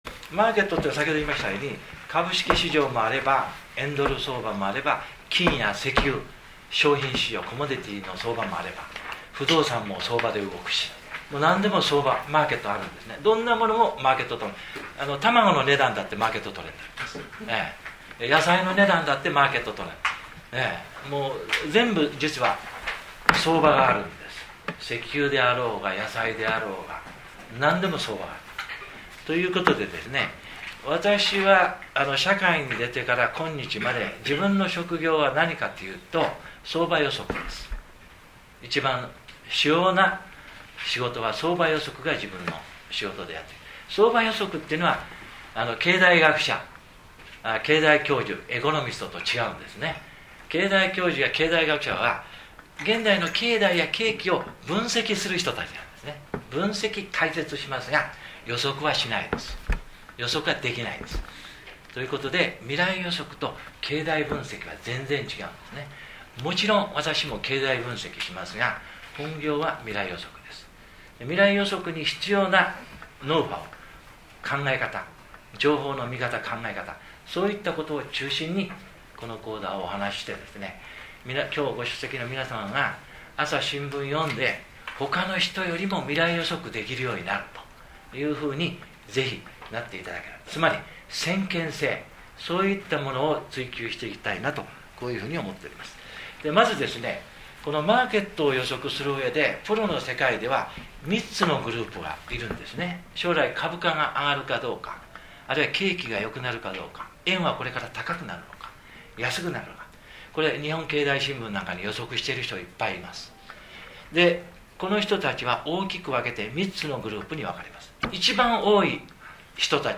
その理由を自身の勉強会で解説した音声を、